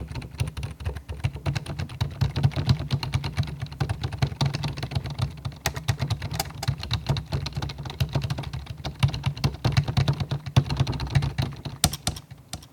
keyboard fast typing without accents delay
computer computer-keyboard delay fast key keyboard letters type sound effect free sound royalty free Memes